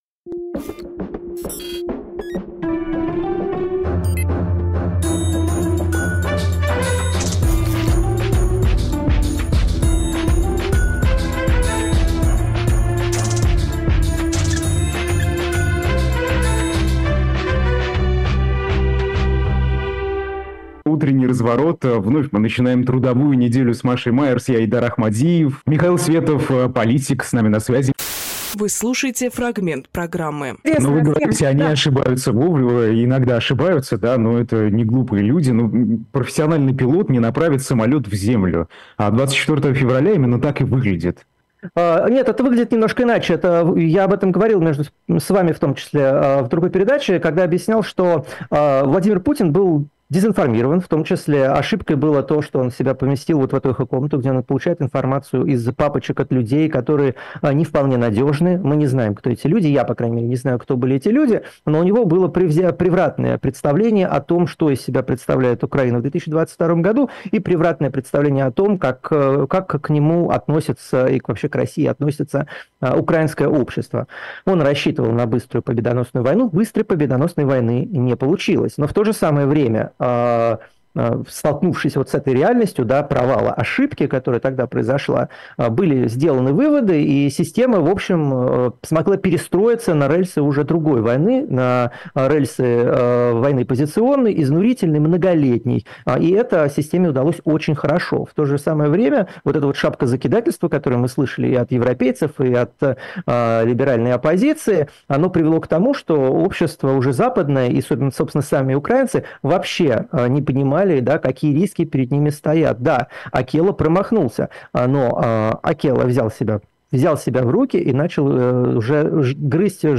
Фрагмент эфира от 31.01